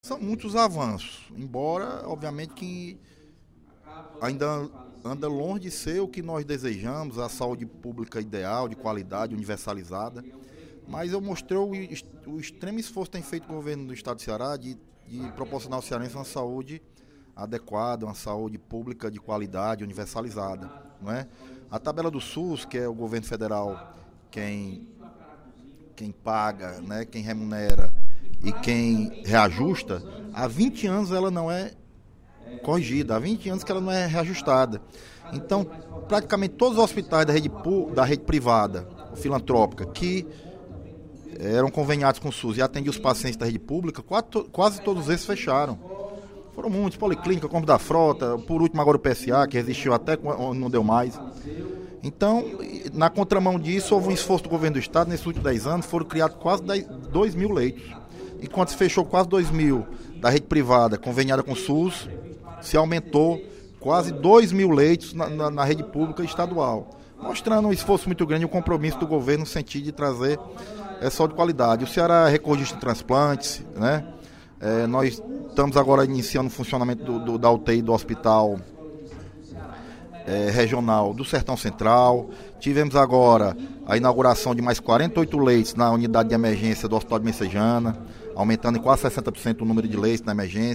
O deputado Leonardo Pinheiro (PP) avaliou, durante o primeiro expediente da sessão plenária  da Assembleia Legislativa desta quinta-feira (06/07), os avanços no setor de saúde pública do Ceará.